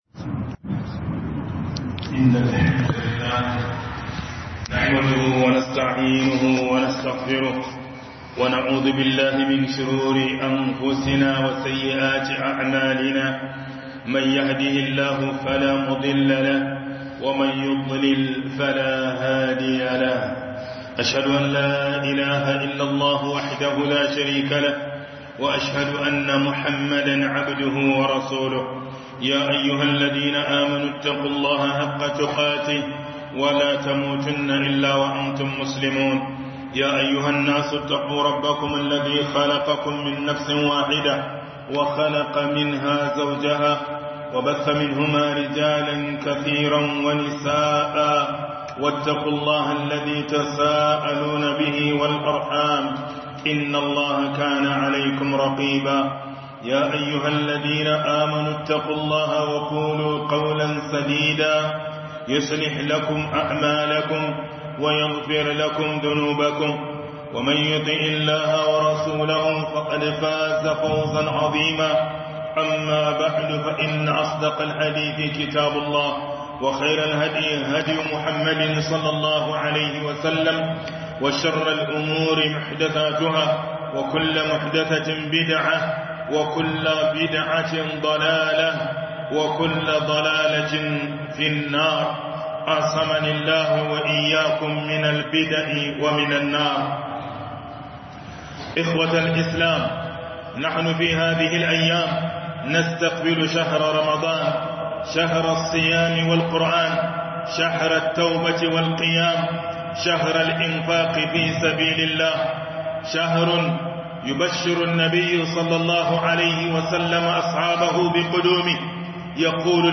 FUSKANTAN WATAN RAMADAN - Hudubar Juma'a